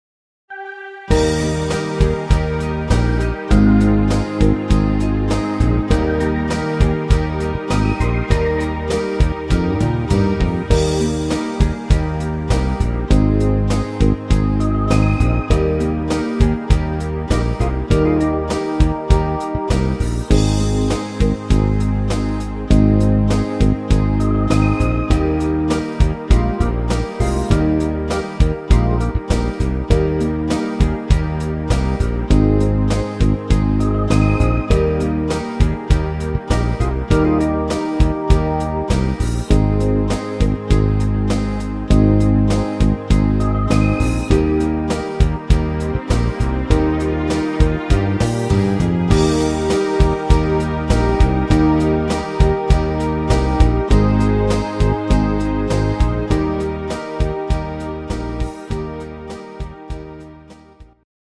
Rhythmus  Medium 8 Beat
Art  Deutsch, Oldies